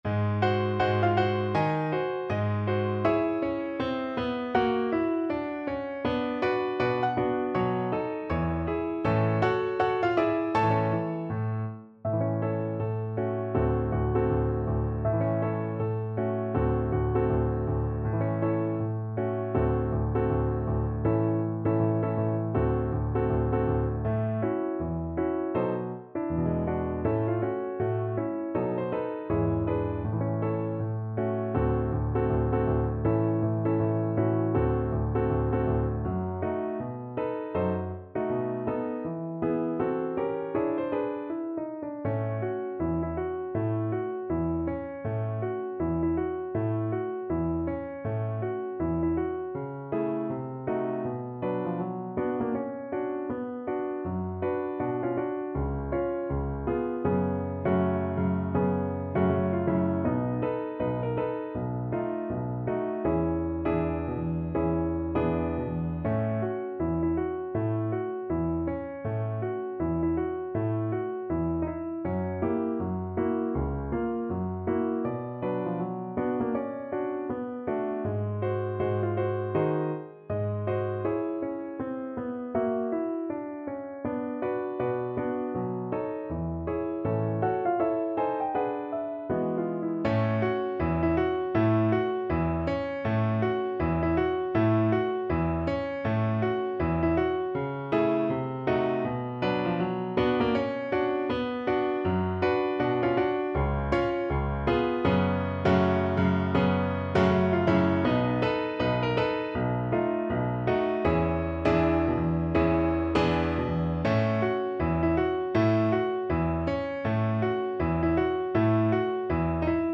~ = 160 Moderato
Jazz (View more Jazz Viola Music)